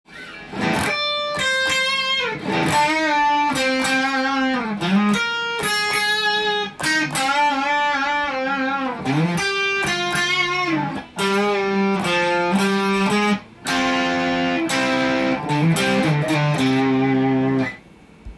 音が小さくなりますがその分　枯れた音がするというギターです。